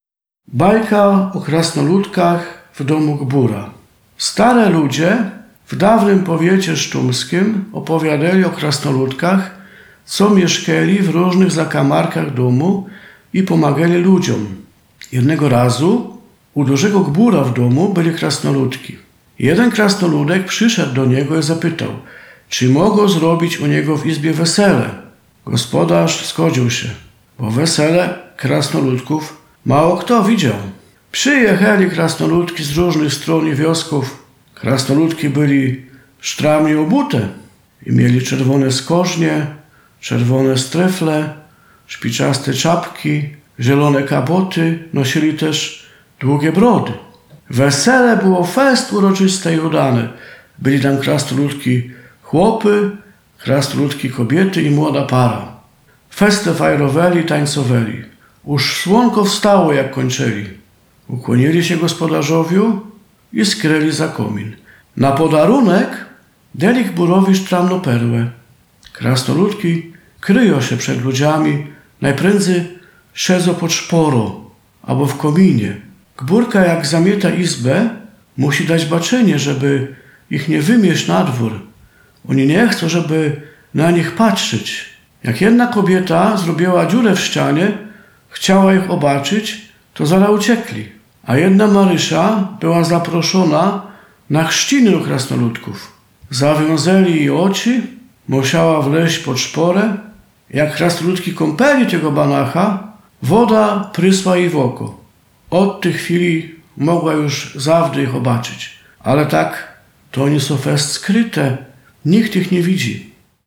Bajka „O krasnoludkach w domu gbura”.